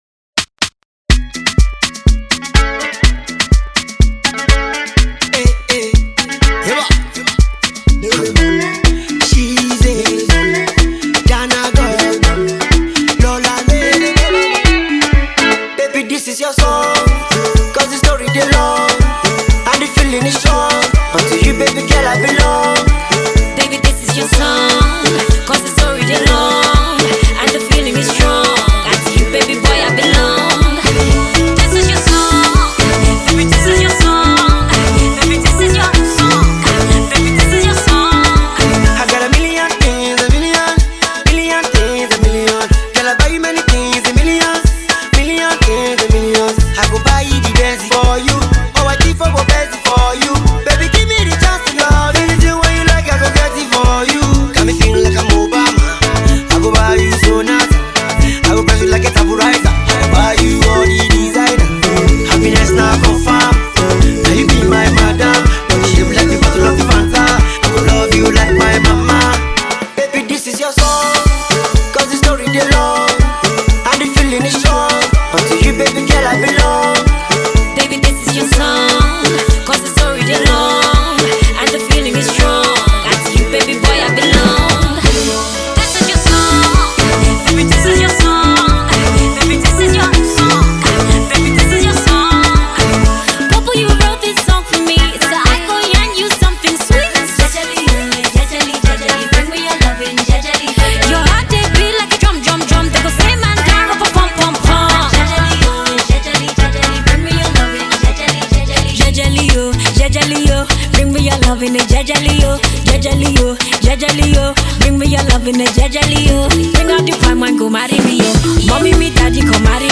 uptempo Song